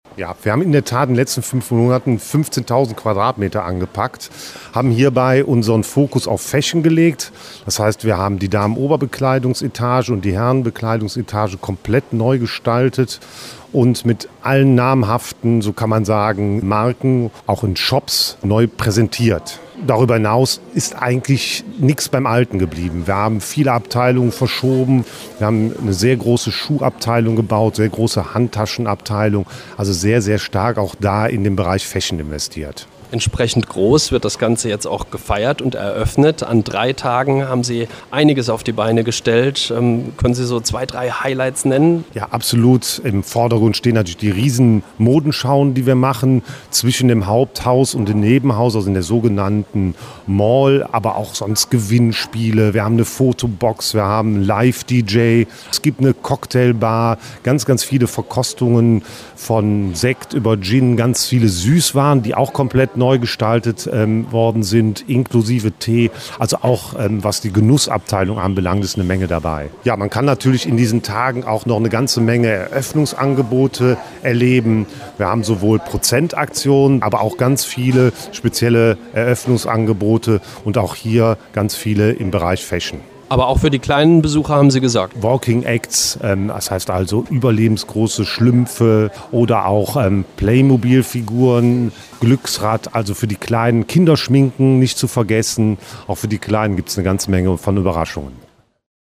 Richtig ordentlich was los ist in diesen Tagen bei der GALERIA Kaufhof in der Adalbertstraße in Aachen. Es gibt guten Grund zum Feiern, denn nach umfangreichen Arbeiten heißt es jetzt „Shoppingwelten neu erleben“!